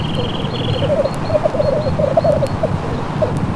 Tetras Lyre (bird) Sound Effect
tetras-lyre-bird.mp3